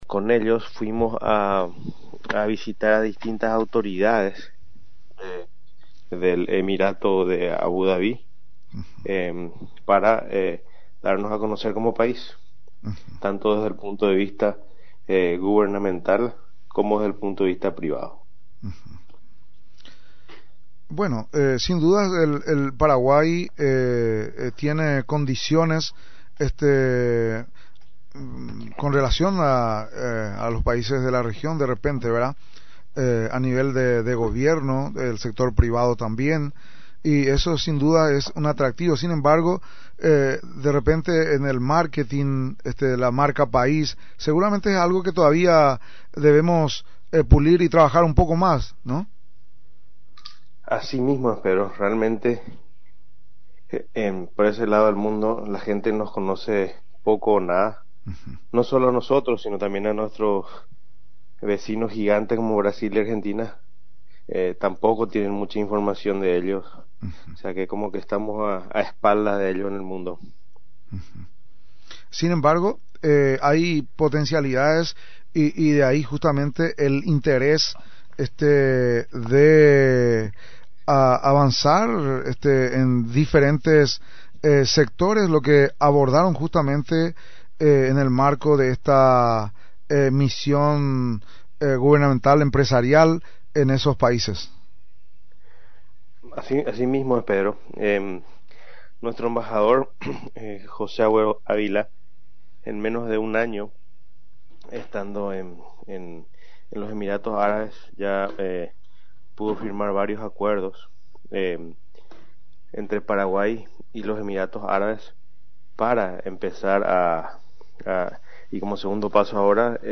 entrevista exclusiva